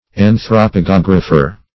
anthropogeographer - definition of anthropogeographer - synonyms, pronunciation, spelling from Free Dictionary
-- An`thro*po*ge*og"ra*pher, n. --